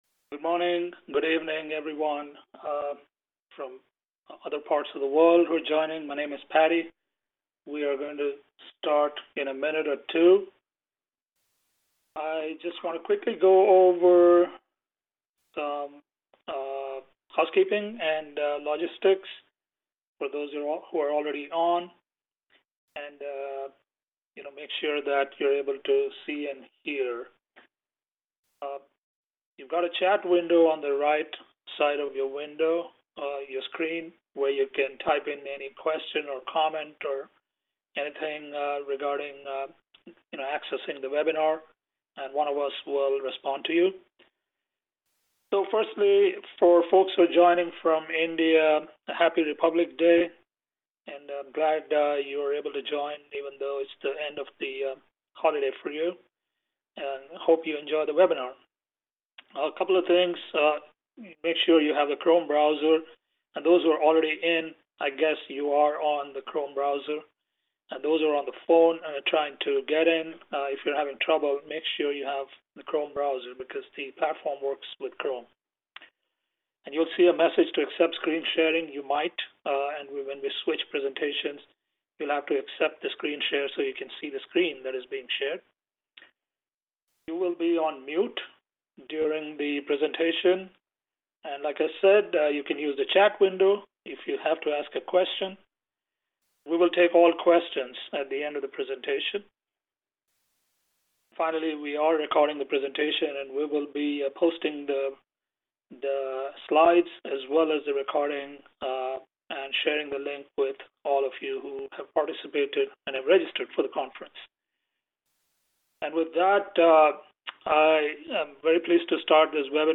Damo-HfS-webinar-healthcare-IT-and-ITES-2017-outlook-.mp3